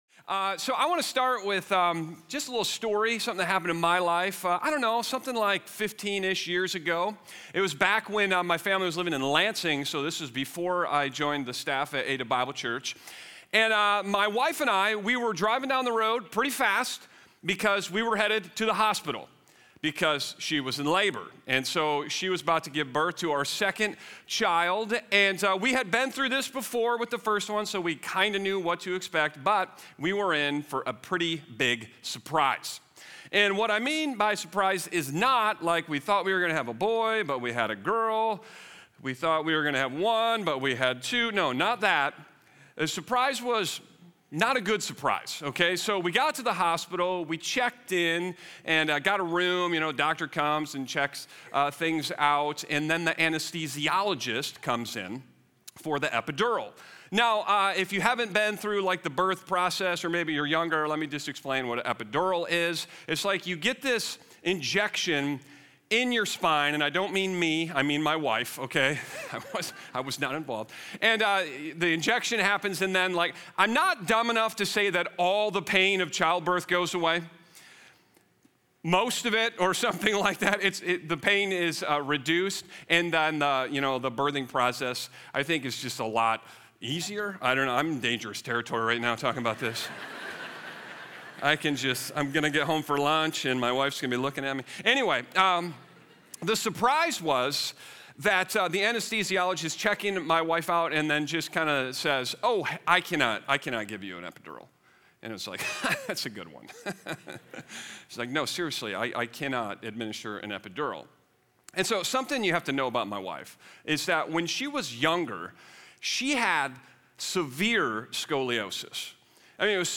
18-30 Show Resources Resources Beyond the Weekend Sermon Discussion Download Audio Listen on Spotify Itunes Audio Podcast Romans 8